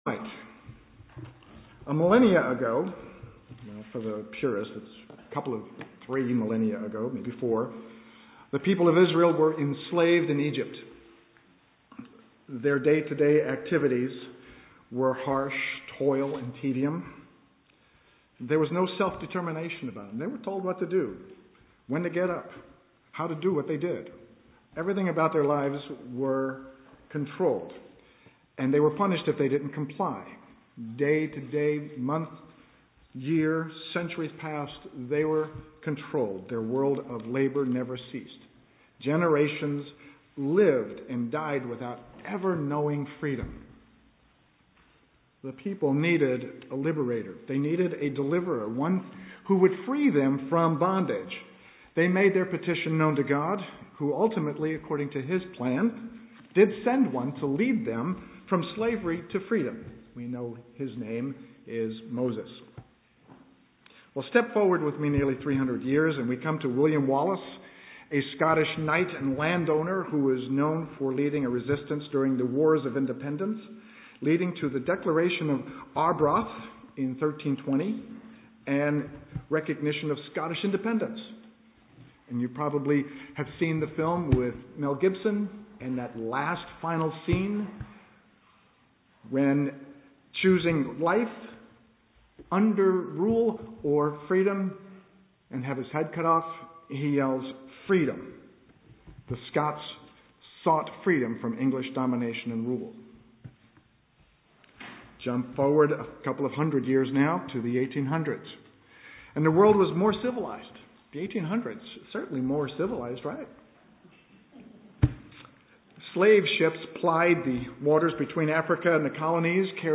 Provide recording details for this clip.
Given in Eureka, CA